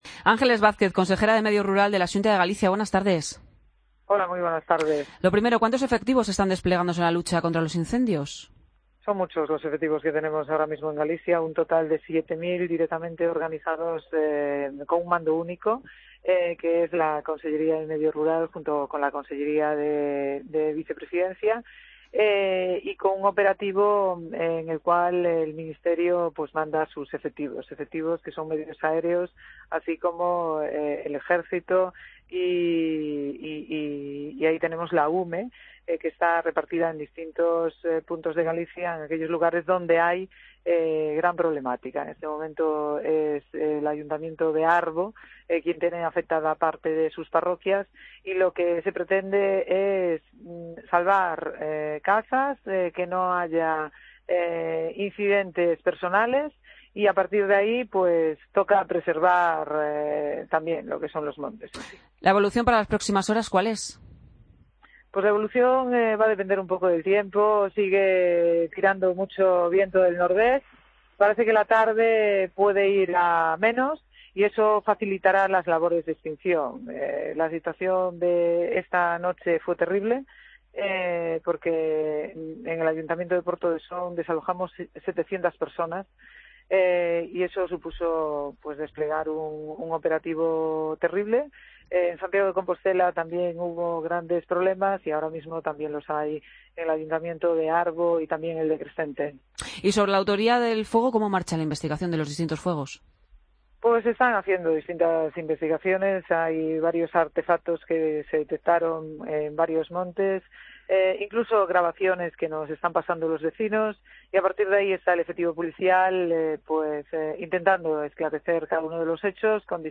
AUDIO: Entrevista con Ángeles Vázquez, Consejera de Medio Rural de la Xunta de Galicia.